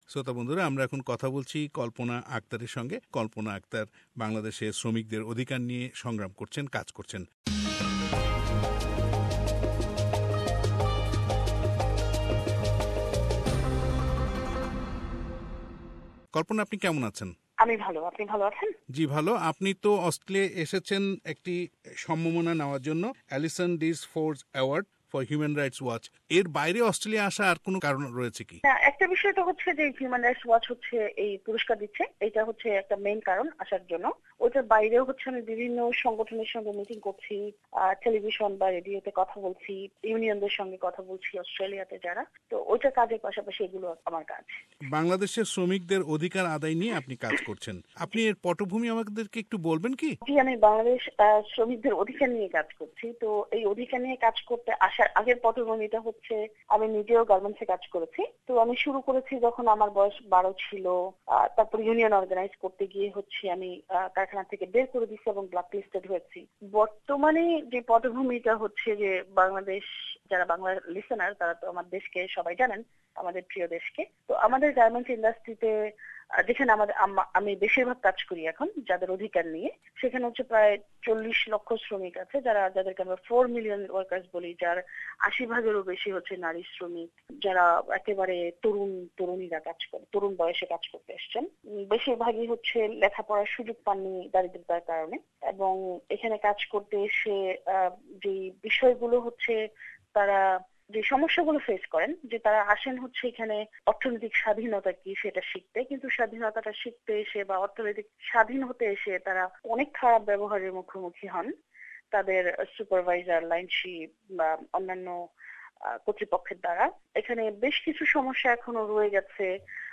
Interview with Kalpona Akter, Bangladeshi labor activist fights for safety at Garment Factories